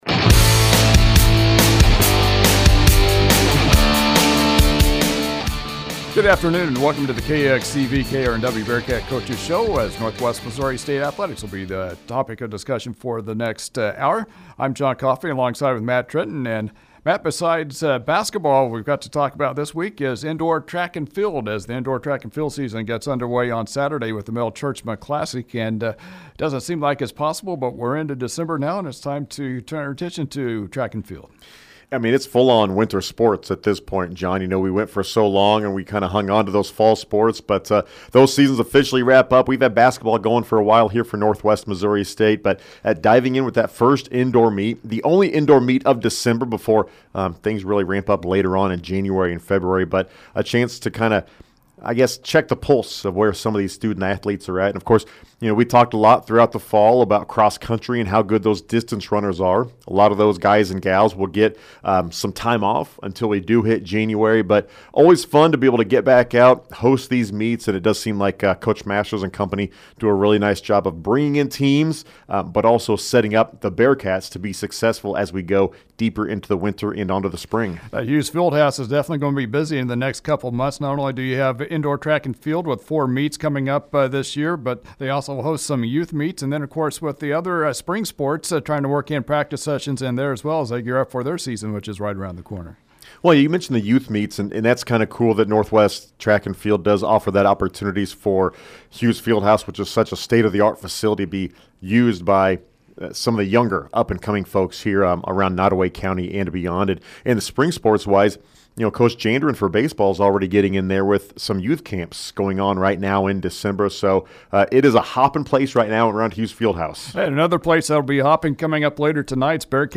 Dec. 3, 2024Coaches Show | Basketball, Track & Field